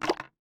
WateredCan.wav